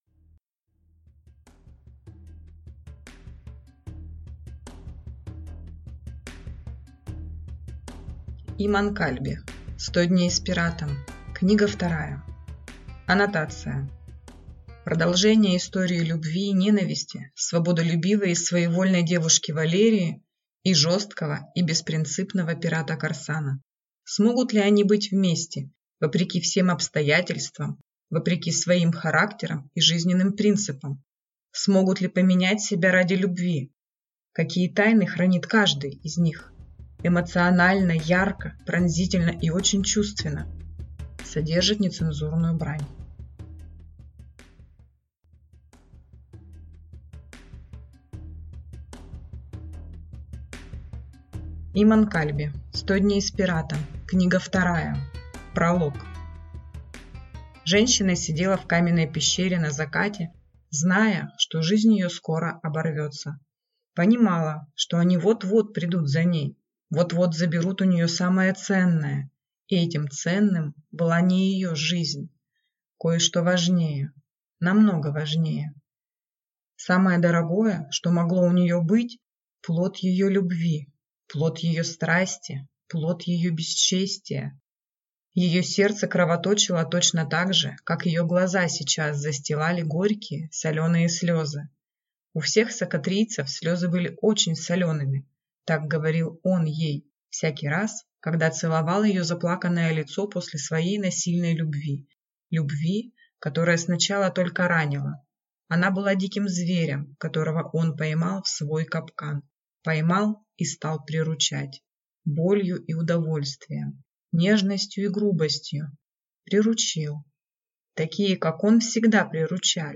Аудиокнига Сто дней с пиратом. Книга вторая | Библиотека аудиокниг